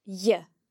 If dh is used before a slender vowel (e or i), it is pronounced similarly to the ‘y’ sound.